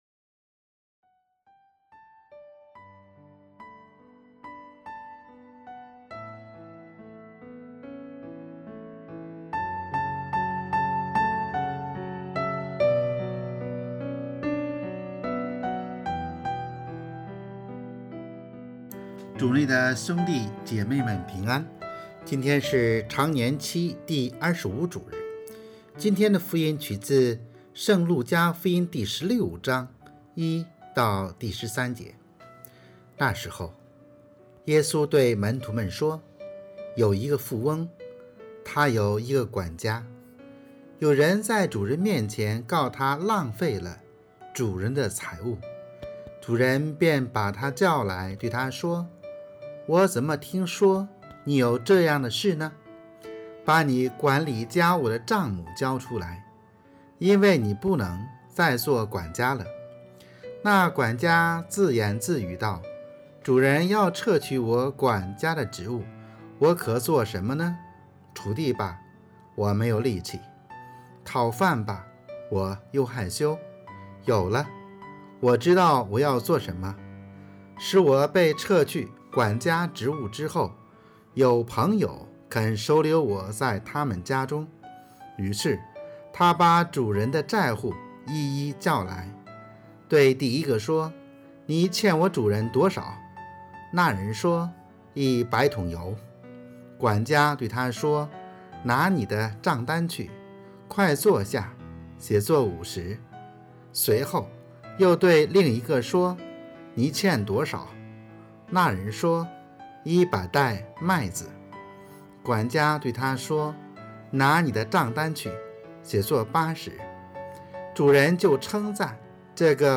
【主日证道】|善用财富（丙-常25主日）